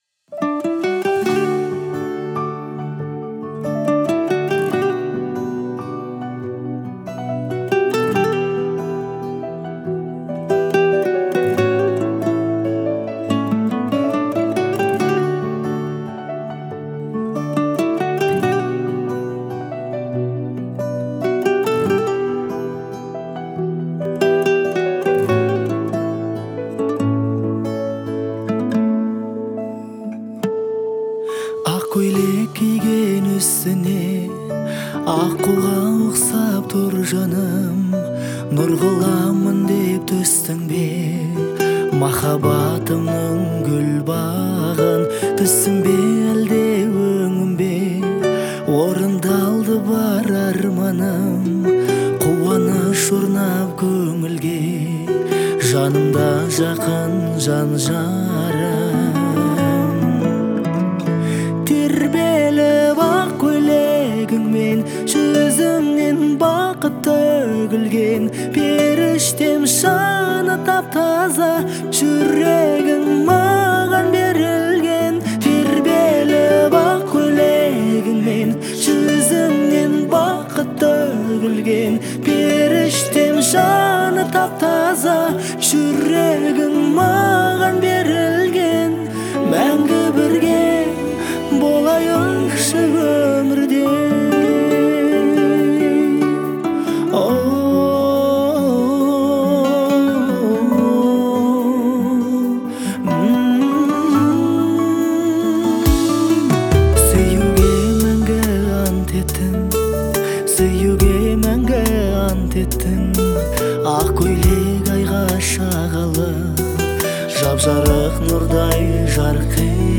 относится к жанру казахской народной музыки